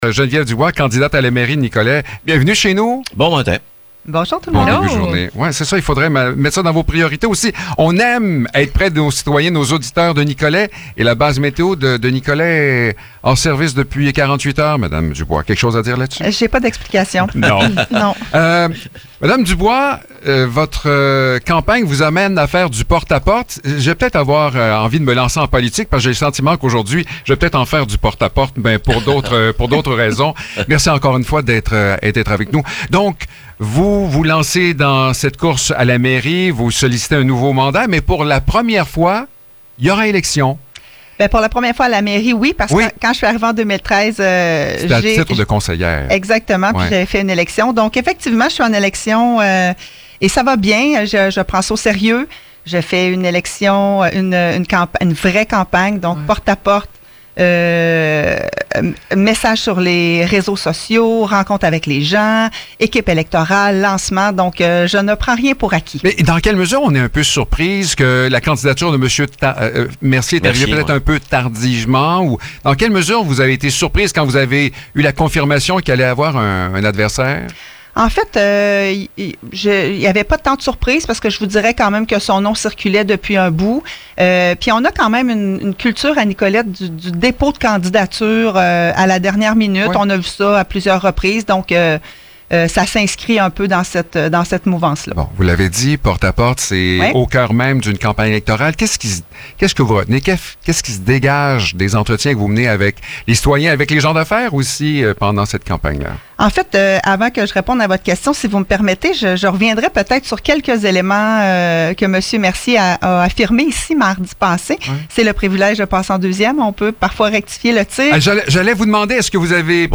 Dans le cadre des entrevues réalisées avec les candidats aux élections municipales.